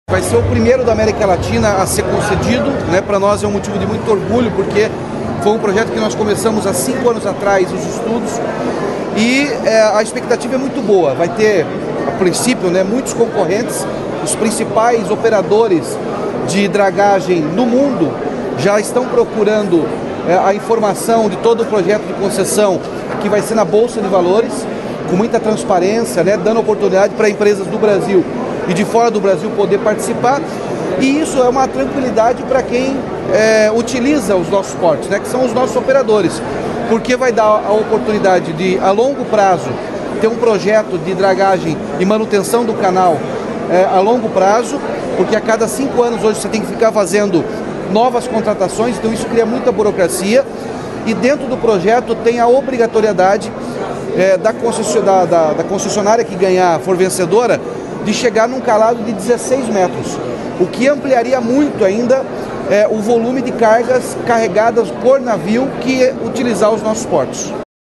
Sonora do governador Ratinho Junior sobre o leilão do canal de acesso ao Porto de Paranaguá